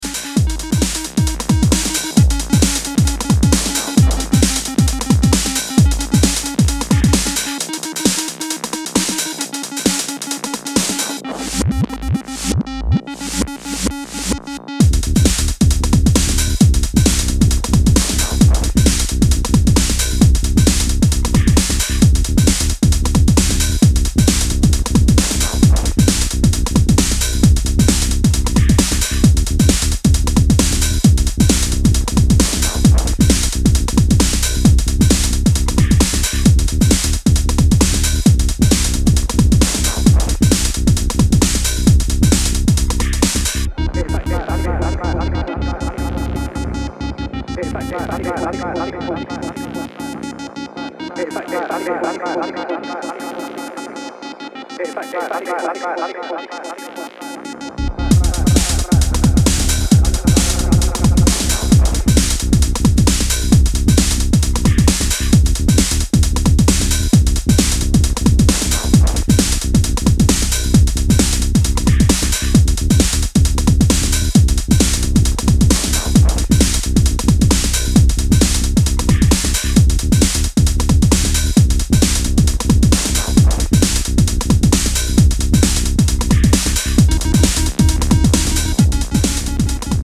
Chunky drums with twists and bleeps.
Techno